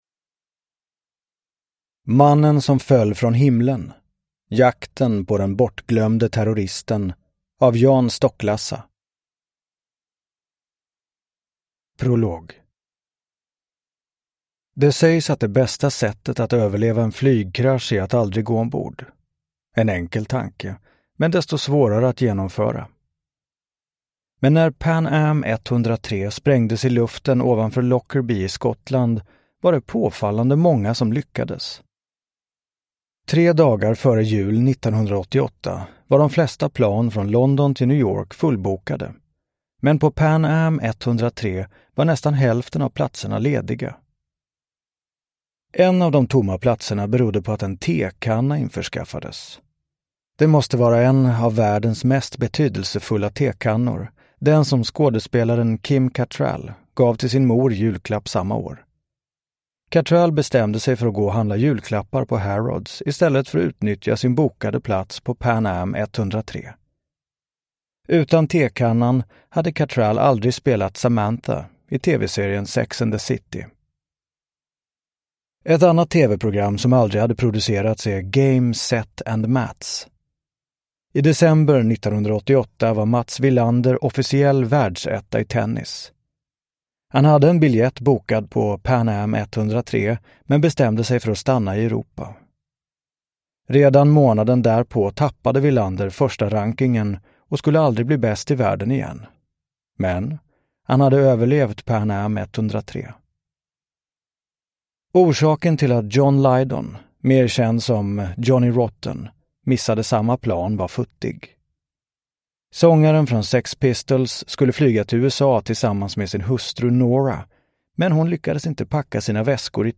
Uppläsare: Martin Wallström